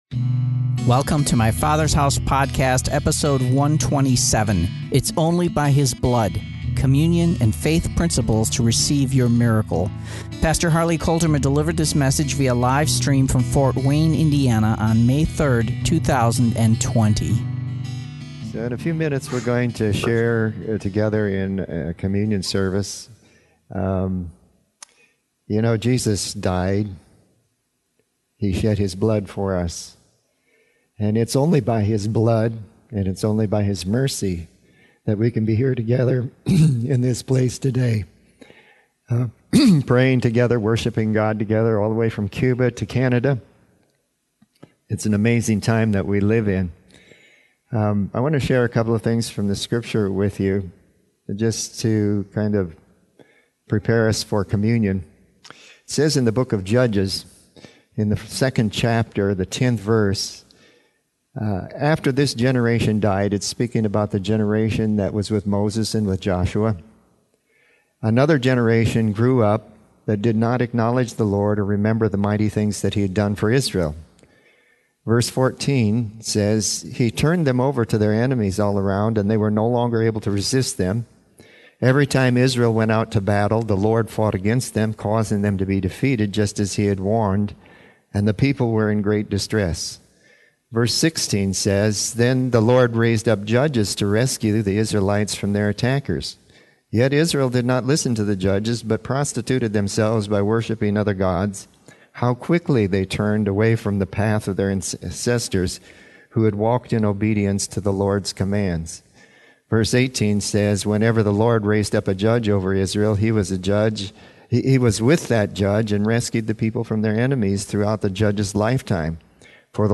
Today in our Communion observance, we discover where the answer lies concerning our lives in the present and our hopes for the future.
In this service, we are releasing faith for your blessing, your sanctification, your deliverance/salvation/healing, your redemption, and finally your forgiveness and cleansing with the cup of the New Covenant.